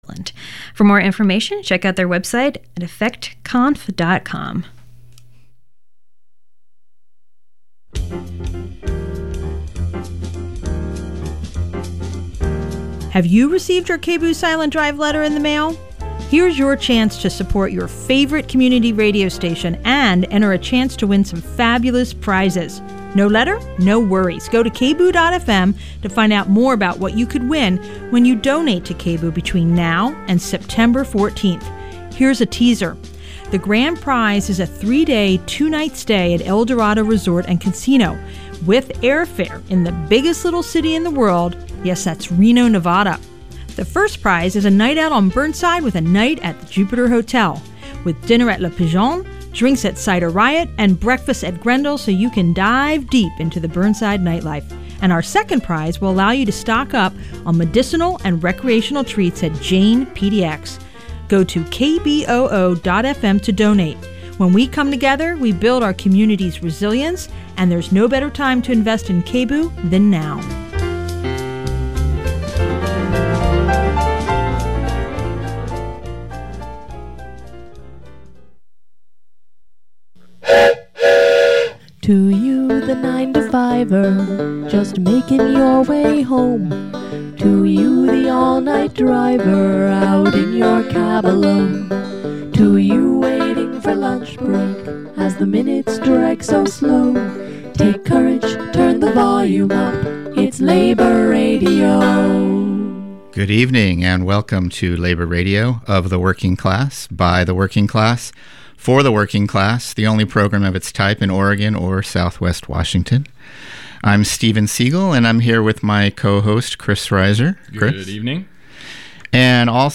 Labor Radio on 10/16/17 - Interview w/ Portland City Council candidate